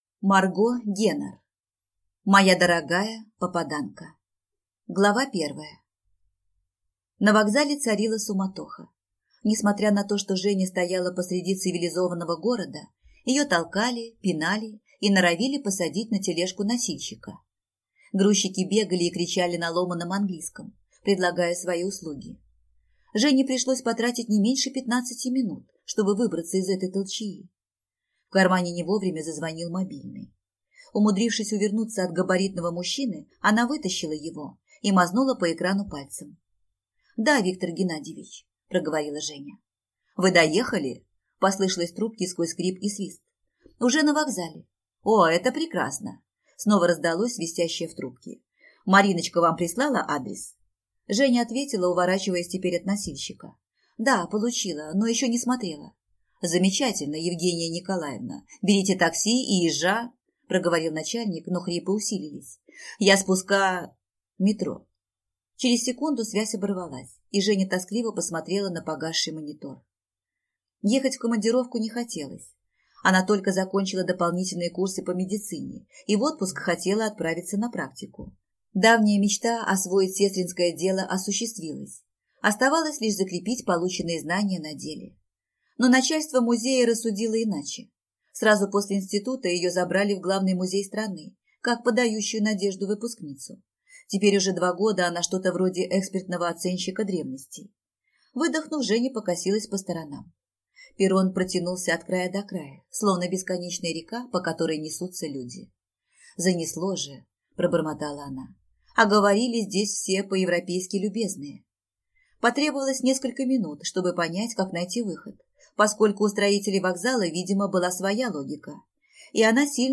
Аудиокнига Моя дорогая попаданка | Библиотека аудиокниг